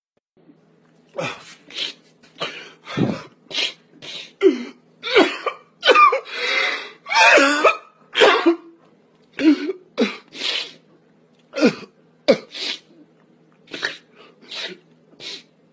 Sound Effects
Funny Crying